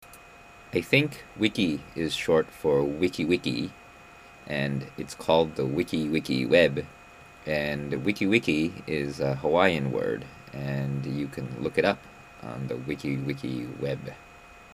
I just want to hear you say the word "wiki" it sounds entirely too cute :)
Well, I don't know how "cute" it is, but I actually wanted to test uploading voice, so here is